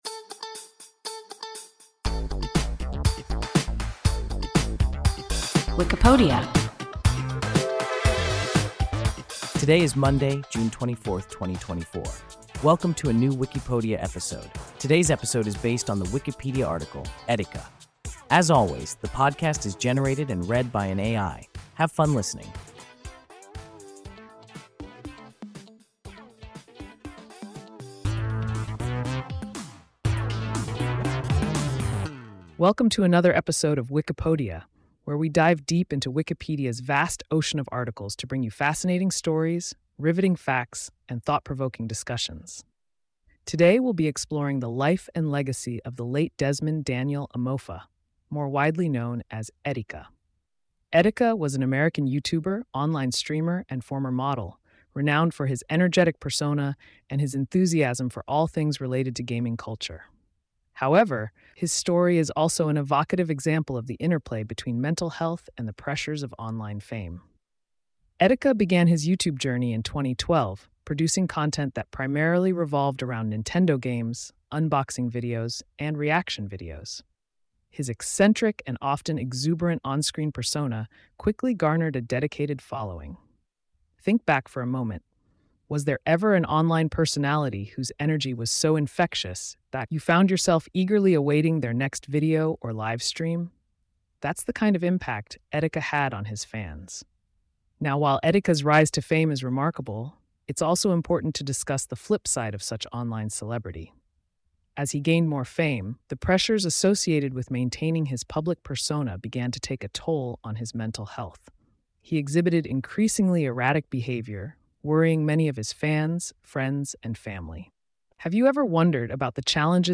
Etika – WIKIPODIA – ein KI Podcast